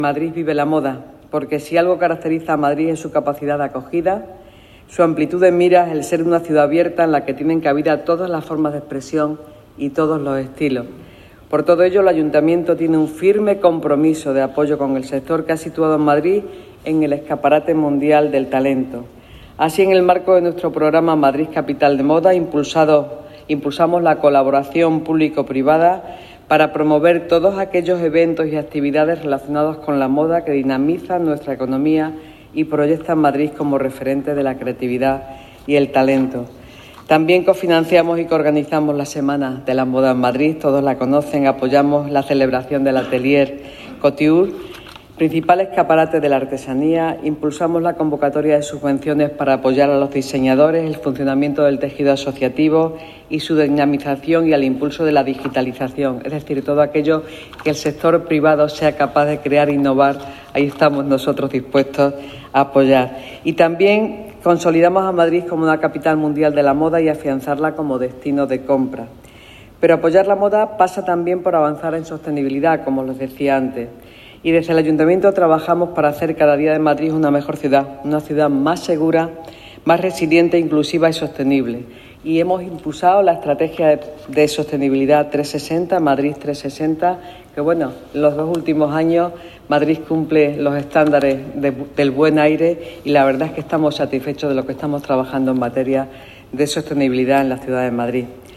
La delegada de Economía, Innovación y Hacienda, Engracia Hidalgo, ha participado hoy en la inauguración de la segunda edición de `Move! Sustainable Fashion Summit´, una jornada empresarial que se consolida como el foro anual de referencia en España dentro del sector de la moda y de su transición hacia un negocio sostenible. Celebrada en La Nave de Villaverde, está impulsada por el diario de información económica enfocado a la moda, Modaes y por la consultora Ernst & Young, con apoyo del Ayuntamiento de Madrid.